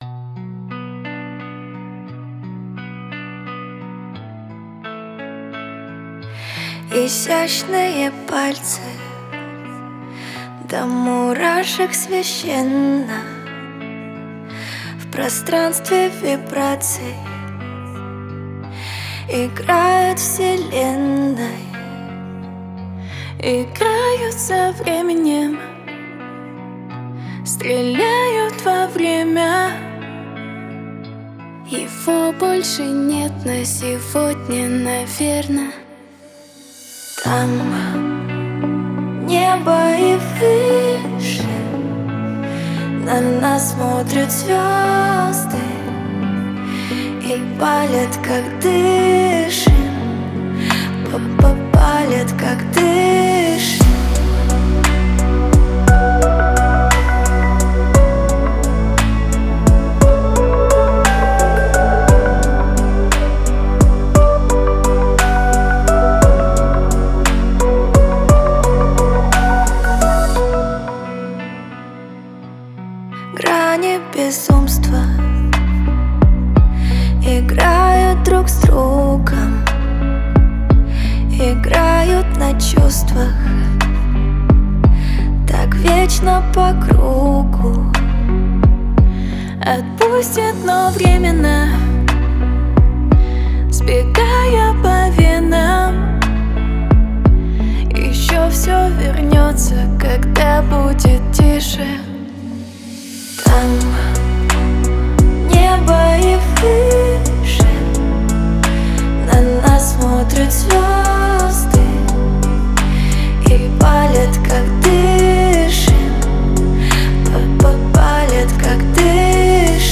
Русские поп песни